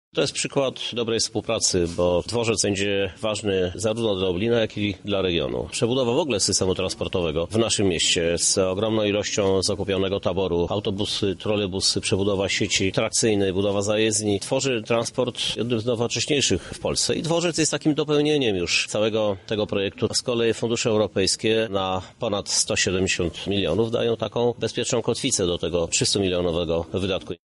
— mówi prezydent Żuk i dodaje, że równie istotnym elementem nowej infrastruktury jest dalsza rozbudowa ulicy Lubelskiego Lipca 80, która ma być główną osią komunikacyjną w tej części miasta.